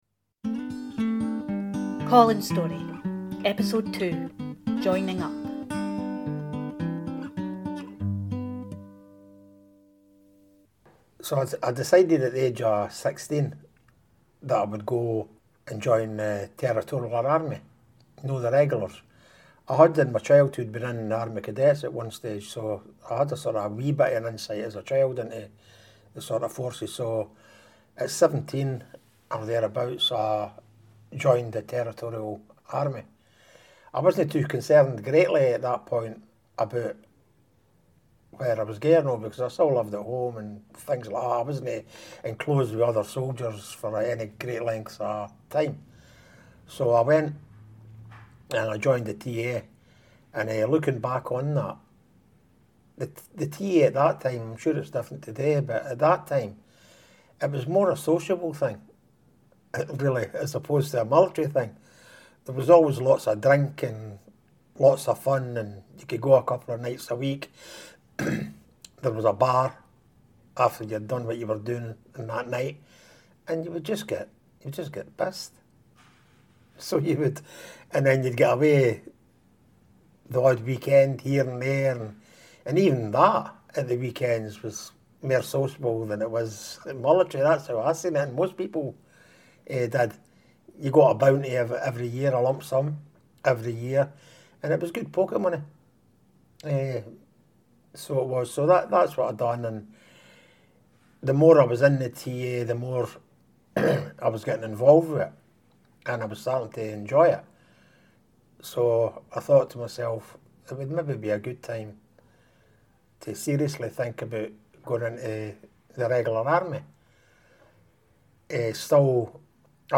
He tells his story with great candour and often, great humour.